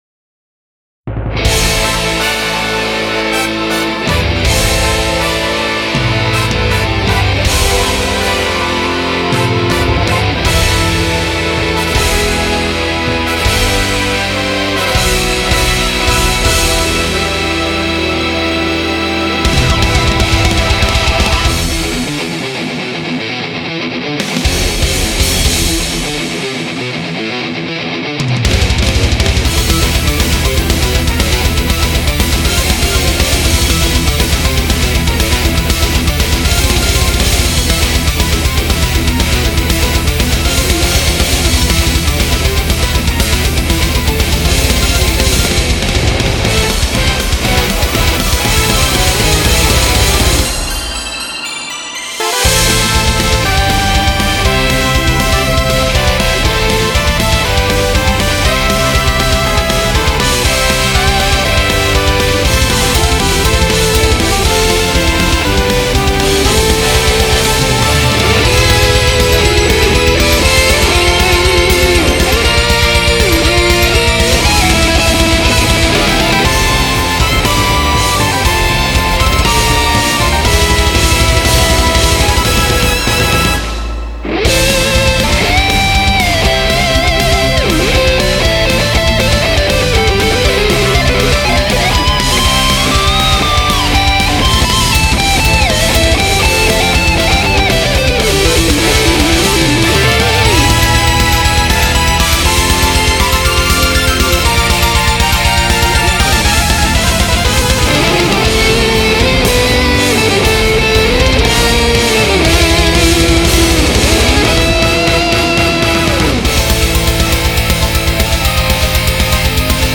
メタルアレンジ。